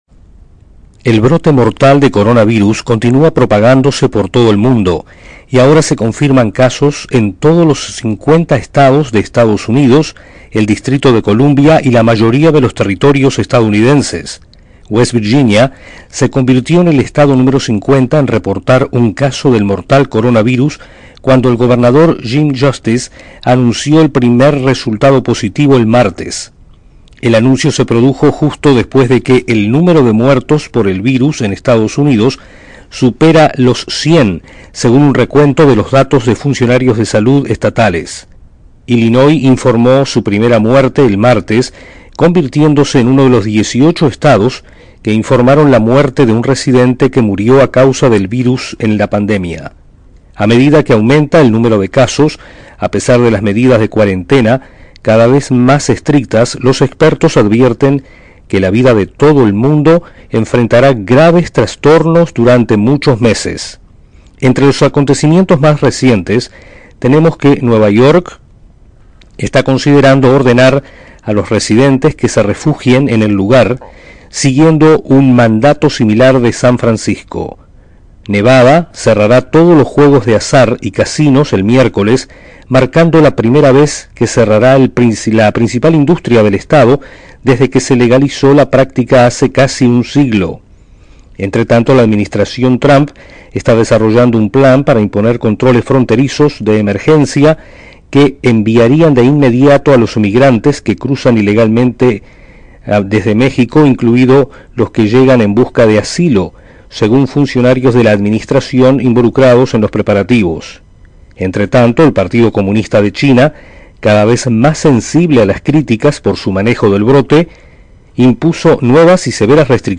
En EE.UU. se han confirmado casos de coronavirus en los 50 estados, mientras el número de muertos sobrepasa los 100. Desde la Voz de América en Washington informa